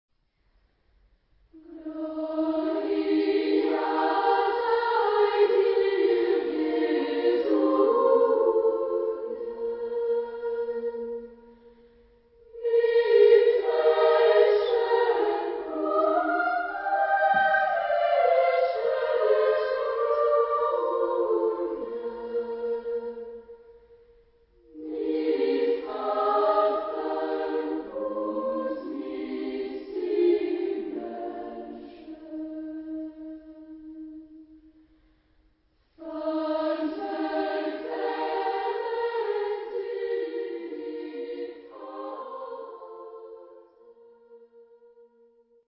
Genre-Style-Forme : Sacré ; Baroque ; Enfants ; Choral
Type de choeur : SAA OU SSA  (3 voix égale(s) d'enfants OU égales de femmes )
Tonalité : mi bémol majeur